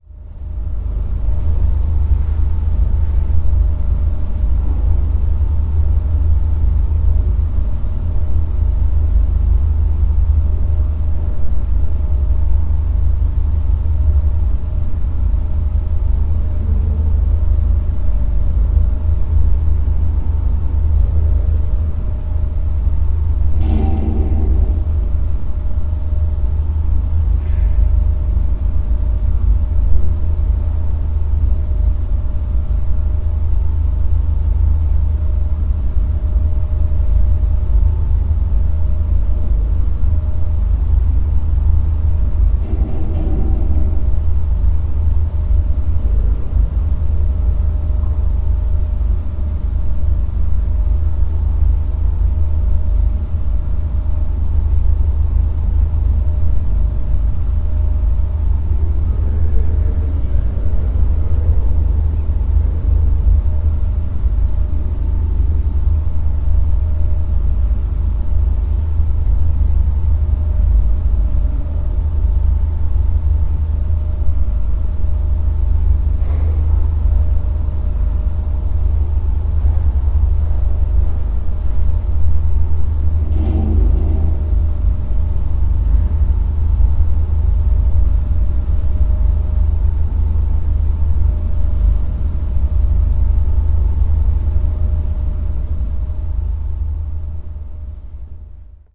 Inside Flat Holm Lighthouse
October 2013, Flat Holm Island.